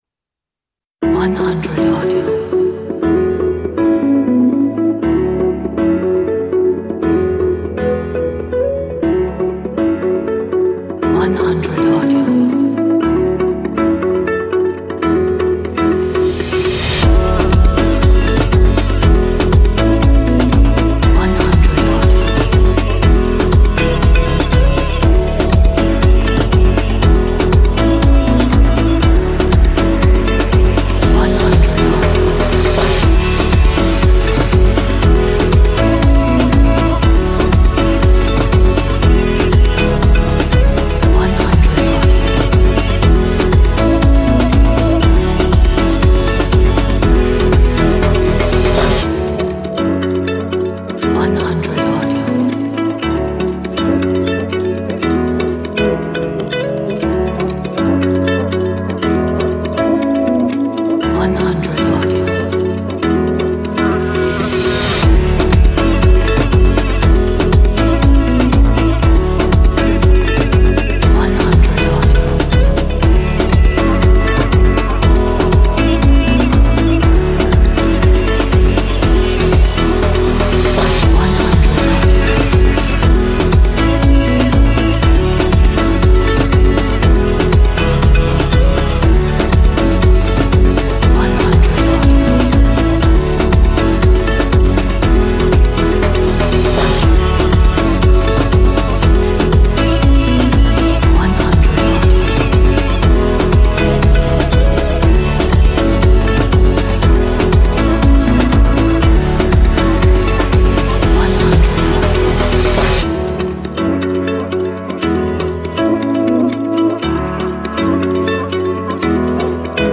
Upbeat Indie Corporate track for your projects.